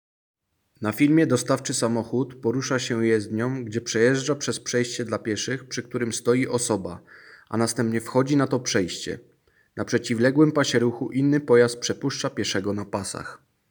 Nagranie audio Audiodeskrypcja_pieszy.m4a